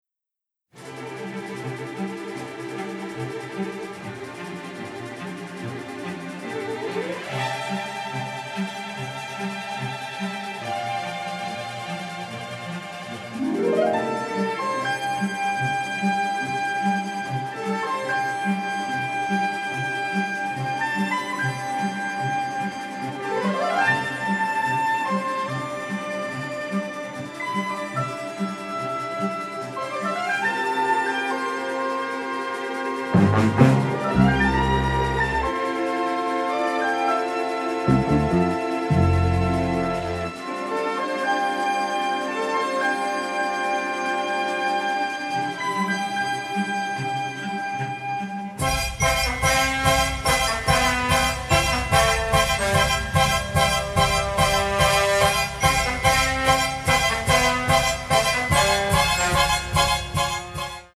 dynamic score